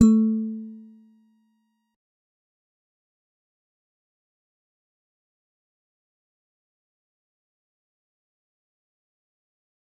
G_Musicbox-A3-mf.wav